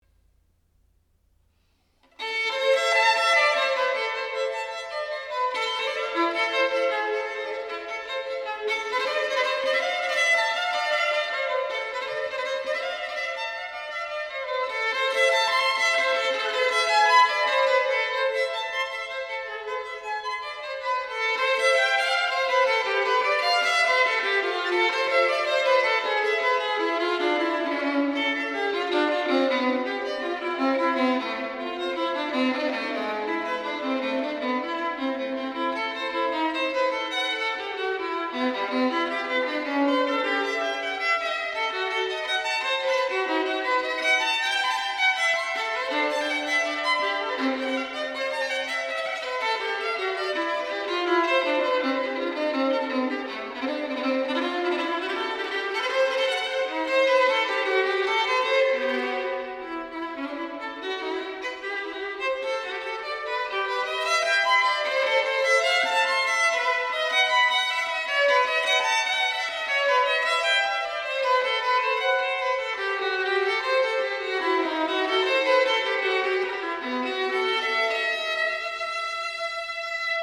古典音樂、發燒音樂